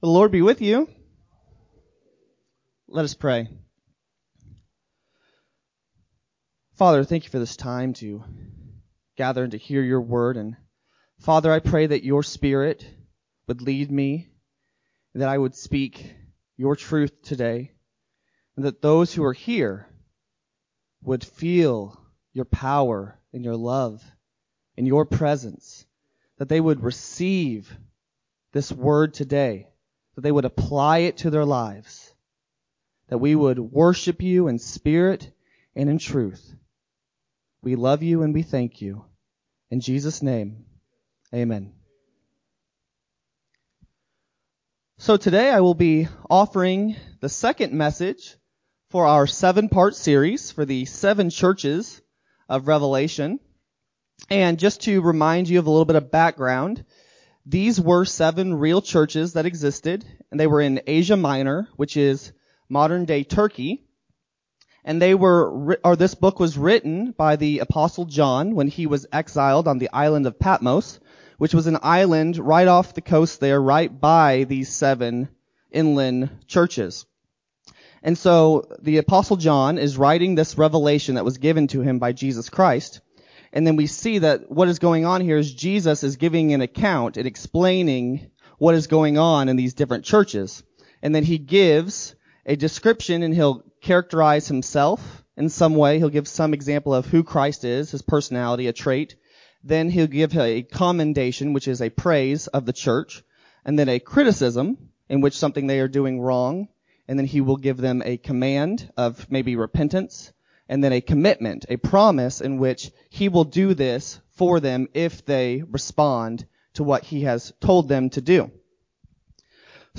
6-7-20-Sermon-CD.mp3